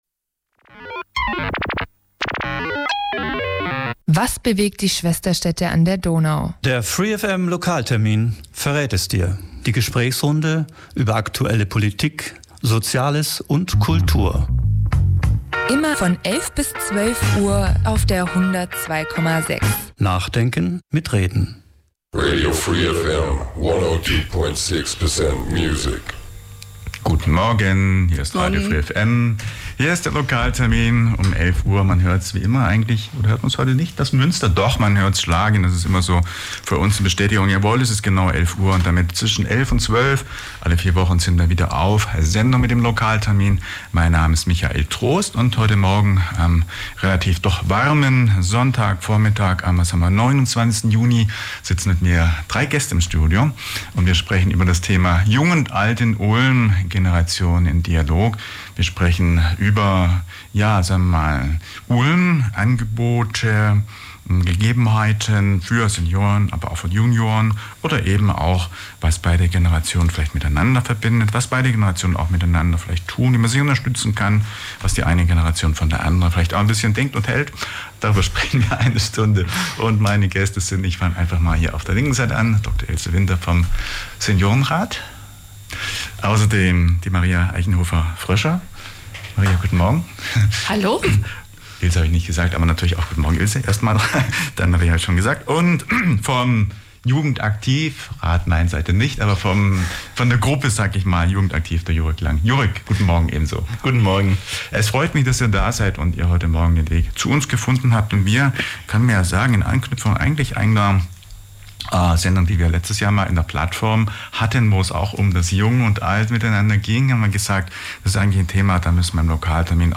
Radio free FM hat Kandidierende aus Ulm und Region zu einem zweistündigen Lokaltermin Spezial eingeladen.